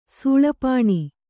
♪ sūḷa pāṇi